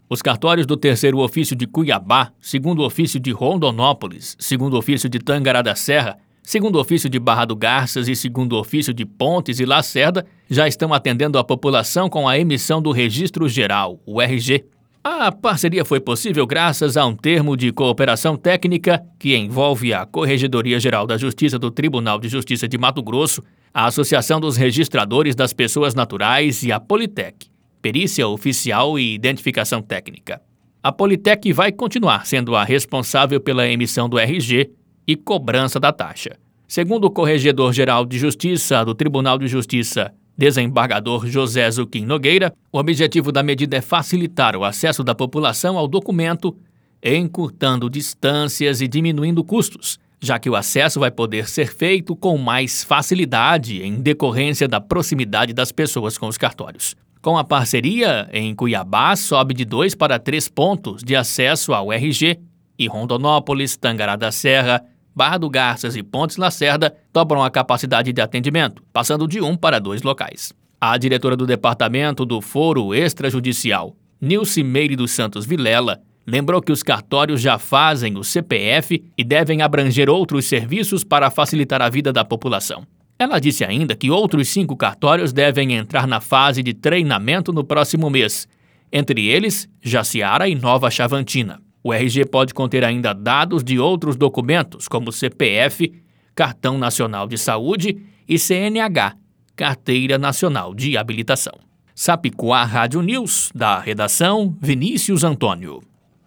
Boletins de MT 16 fev, 2022